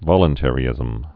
(vŏlən-tĕrē-ĭzəm)